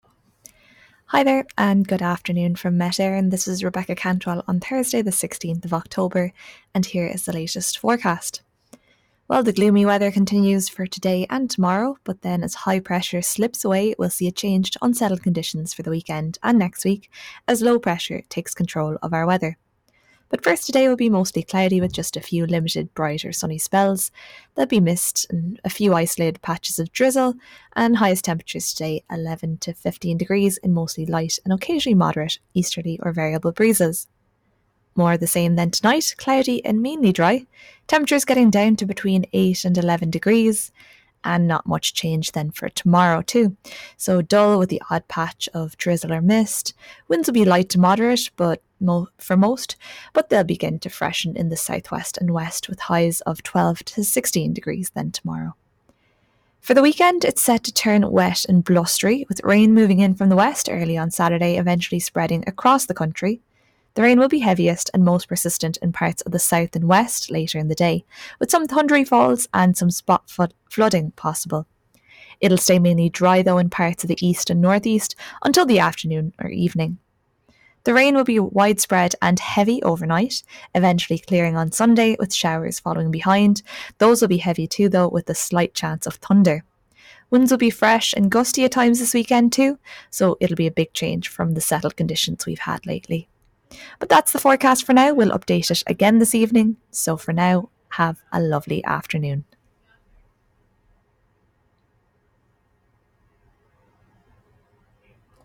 Weather Forecast from Met Éireann